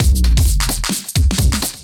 OTG_DuoSwingMixD_130b.wav